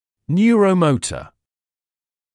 [ˌnjuərə(u)’məutə][ˌньюро(у)’моутэ]нейромоторный; нервно-мышечный